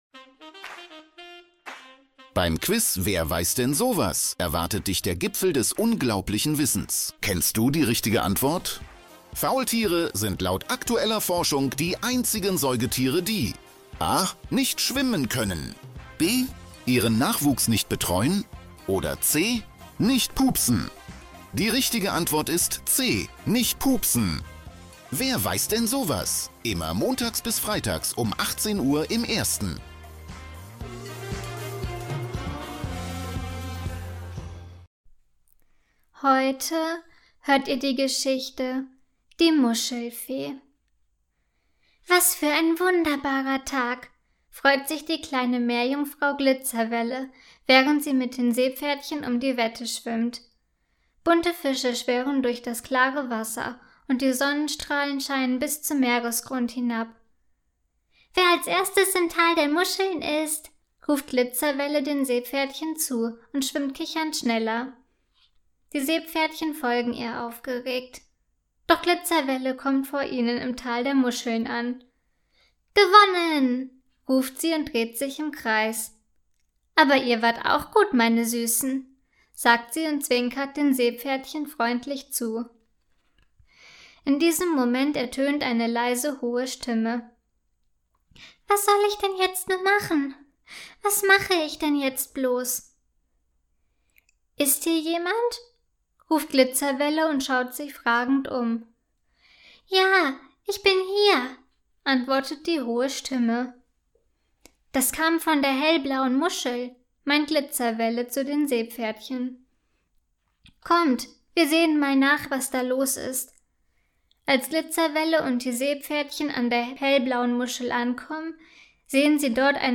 Kindergeschichten mit garantiertem Happy End